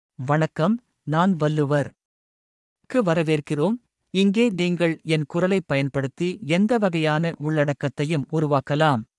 Valluvar — Male Tamil AI voice
Valluvar is a male AI voice for Tamil (India).
Voice sample
Listen to Valluvar's male Tamil voice.
Male
Valluvar delivers clear pronunciation with authentic India Tamil intonation, making your content sound professionally produced.